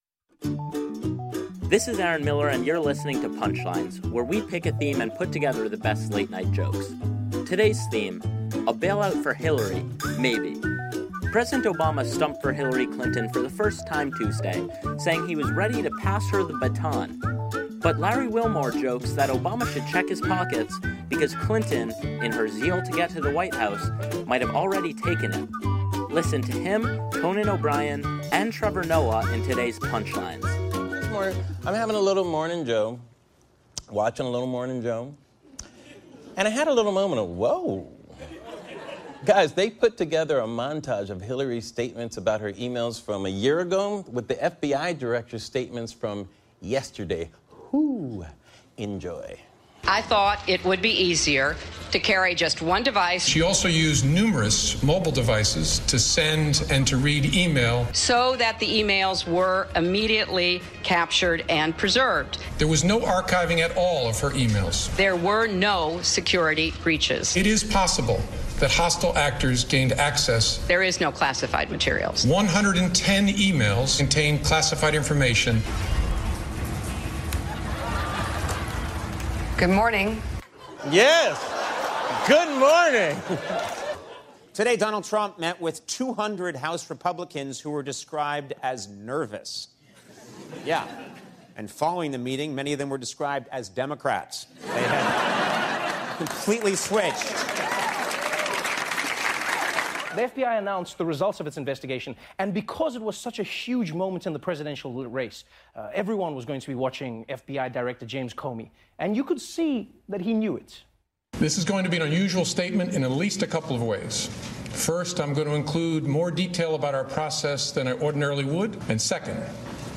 The late-night comics give the latest on the Clinton email saga, including Obama stumping for the candidate in an attempt to distract the public and save her reputation.